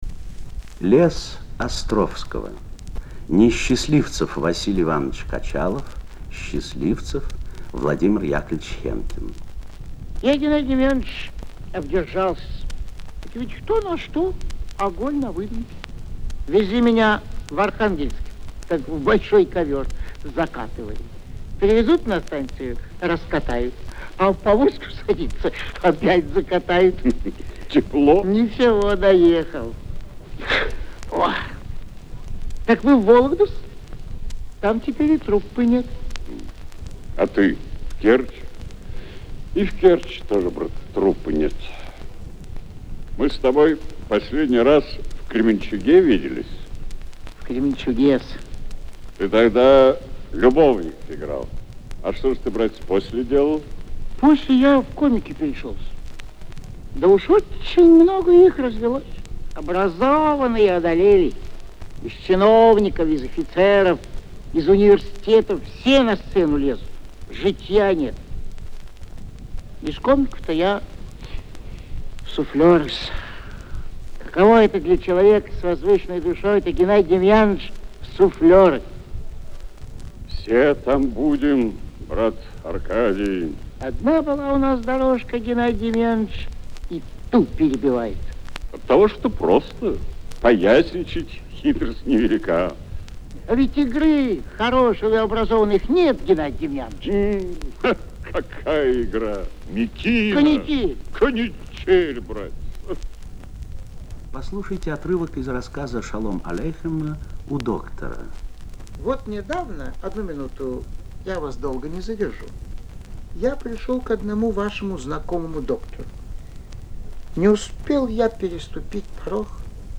Звуковая страница 12 - Театр 'Кругозора'. На сцене - Владимир Хенкин.